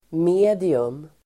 Uttal: [m'e:dium]